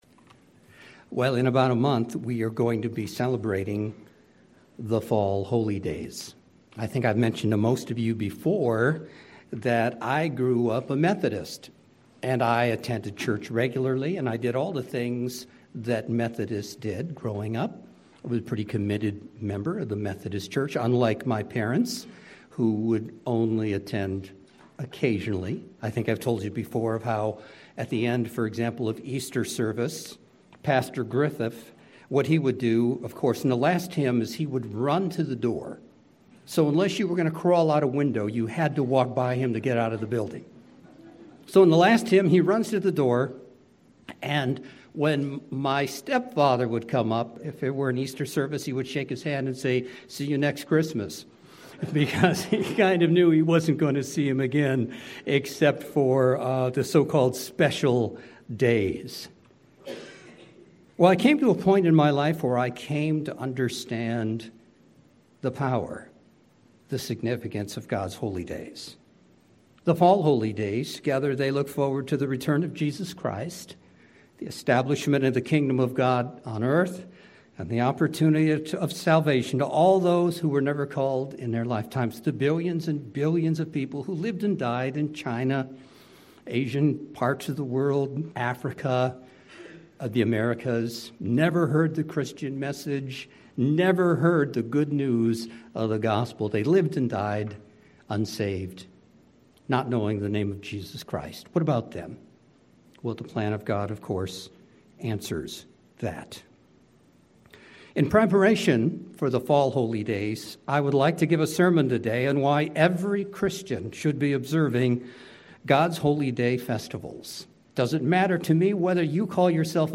Together they look forward to the return of Jesus, the establishment of the Kingdom of God, and the opportunity of salvation to all who never were called in their lifetimes. In preparation for these coming Holy Days, I would like to give a Sermon today on why every Christian should be observing God's Holy Day Festivals.